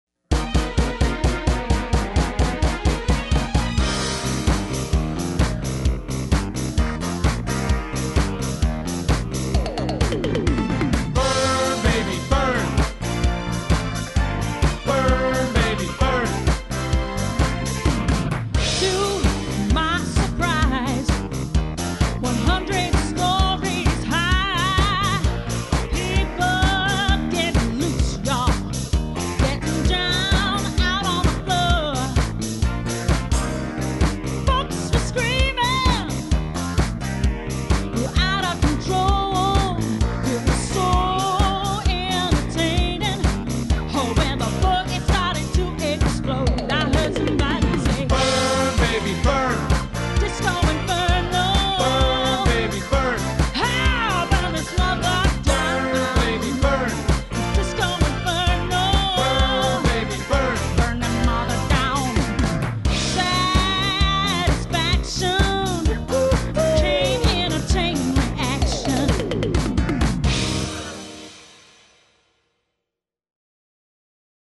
My drum parts
Dance/R&B/Disco